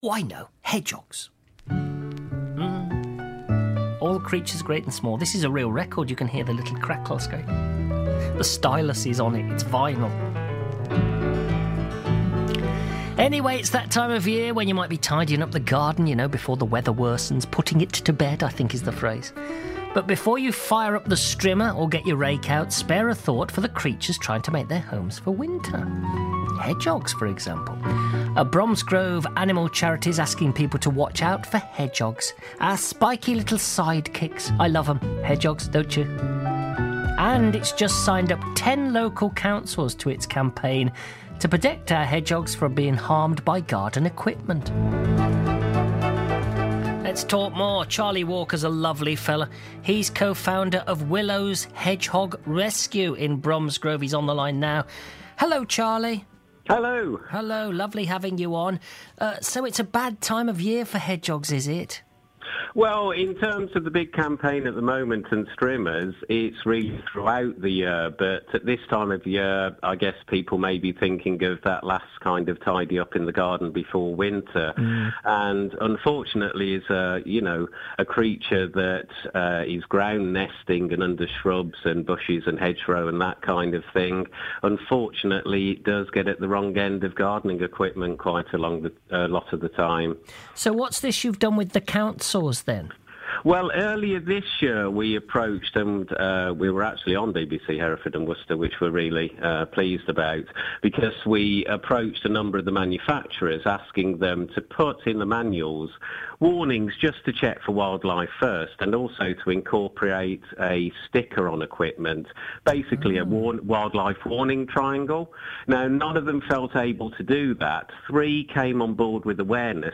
Seven minute interview